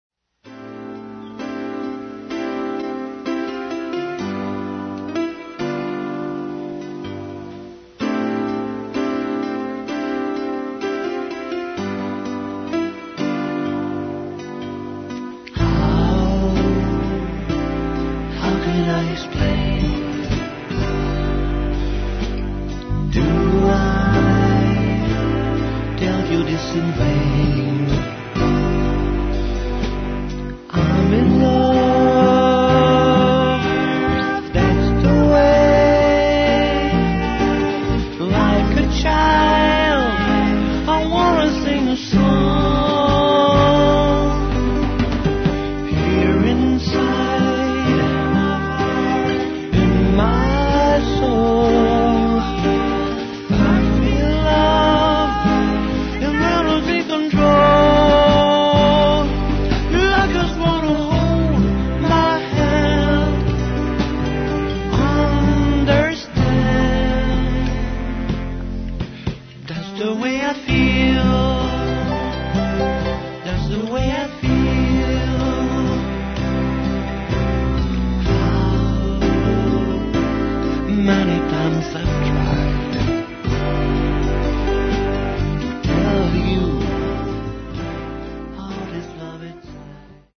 Genre: Folk Année de réalisation: 1997 Technique utilisée: Histoire: Pi�ce compos�e pour un autre artiste.